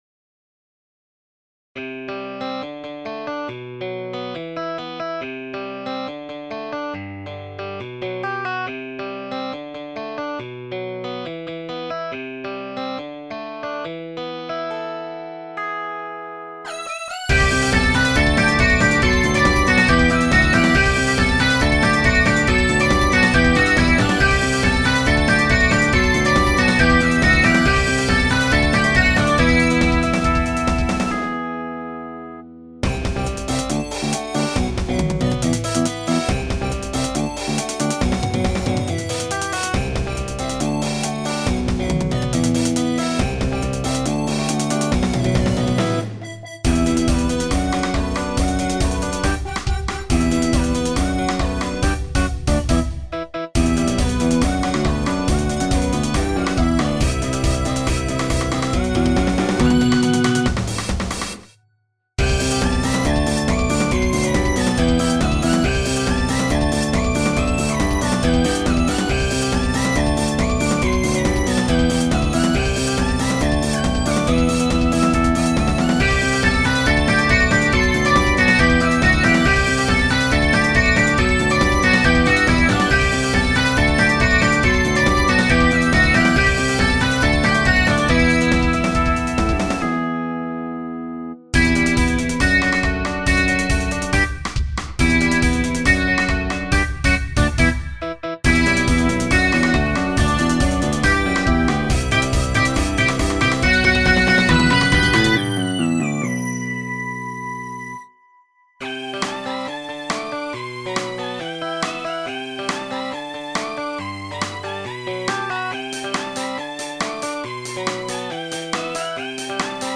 カラオケ音源 bpm139